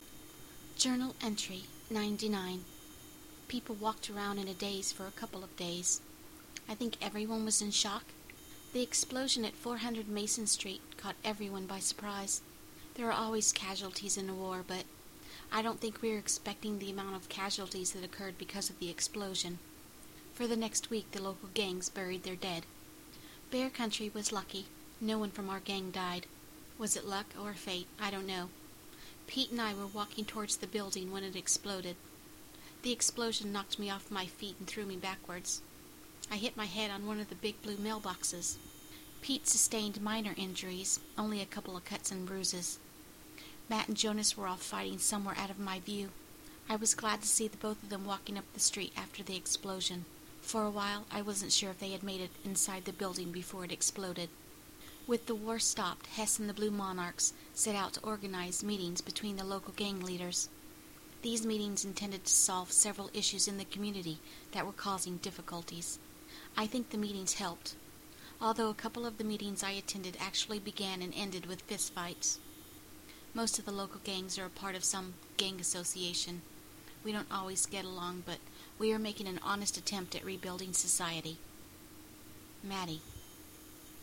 Genre: fiction, audio.